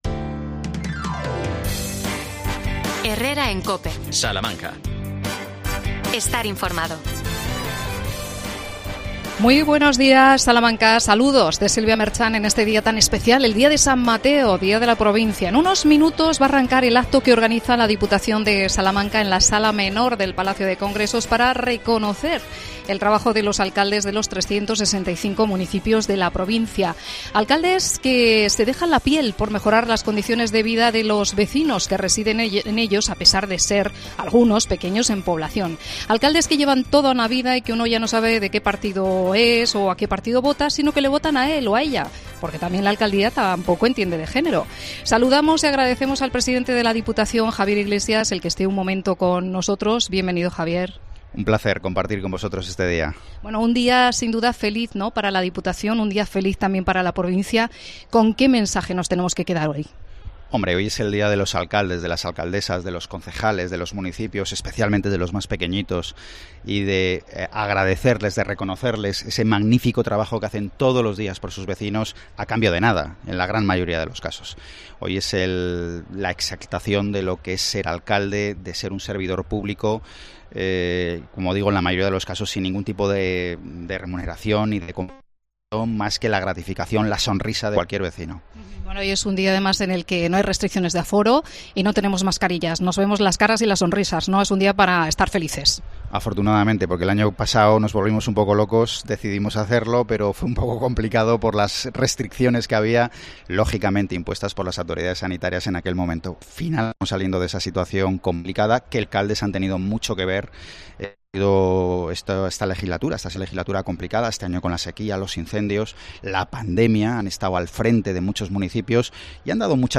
Desde el palacio de Congresos te contamos la celebración del día de la provincia de Salamanca que organiza la diputación provincial.